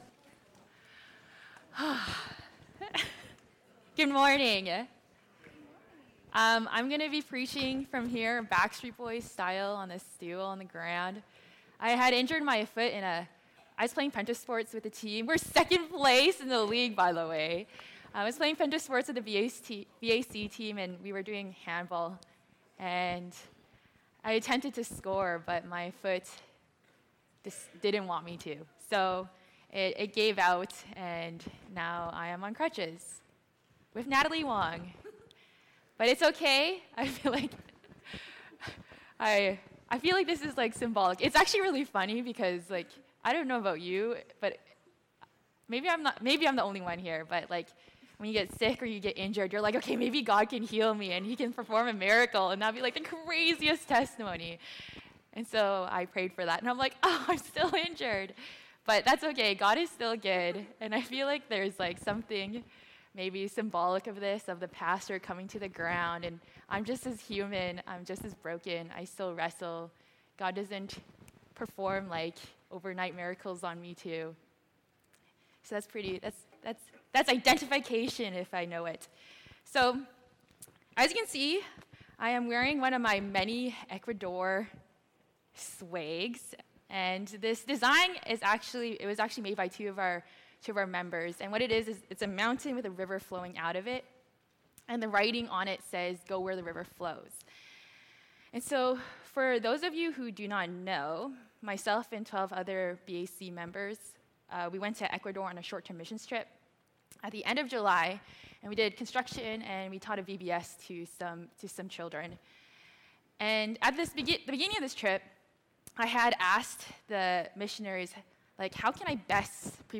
Sermons 講道 | Burnaby Alliance Church